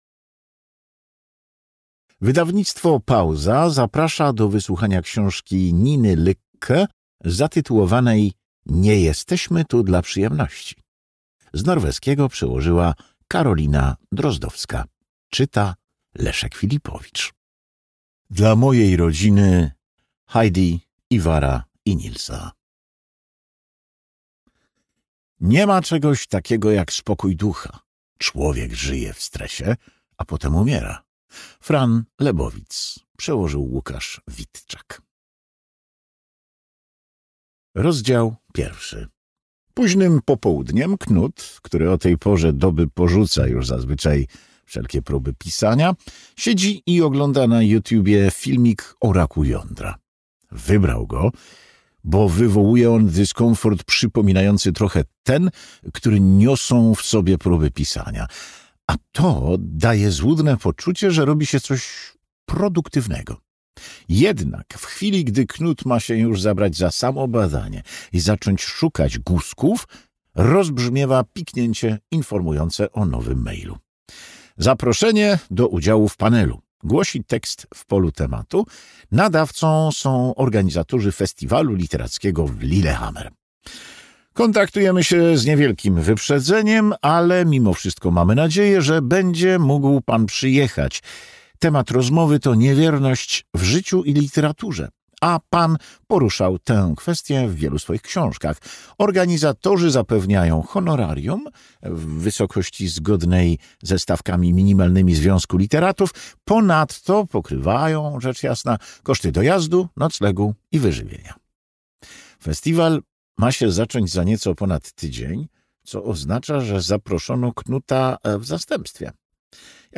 Nie jesteśmy tu dla przyjemności (audiobook) – Wydawnictwo Pauza